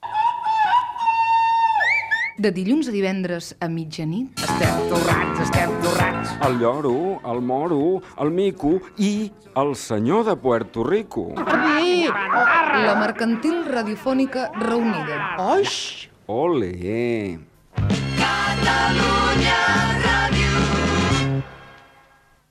Promoció del programa i indicatiu emissora
FM